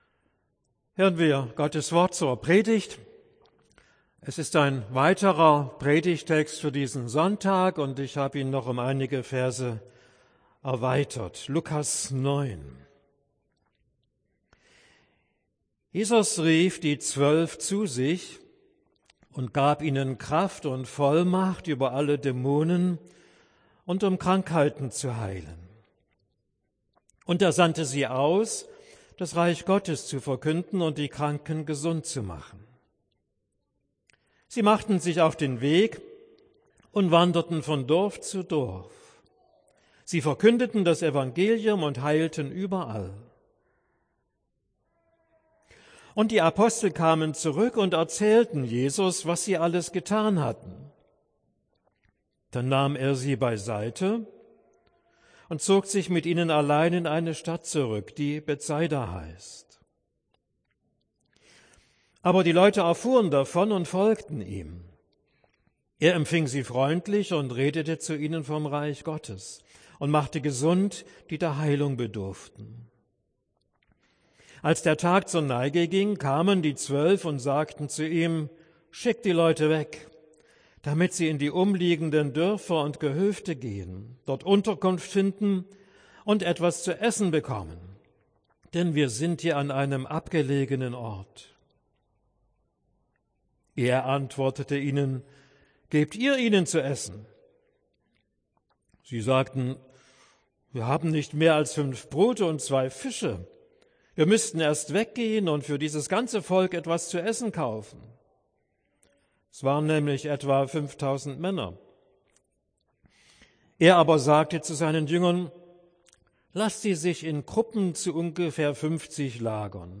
Predigt für den 7. Sonntag nach Trinitatis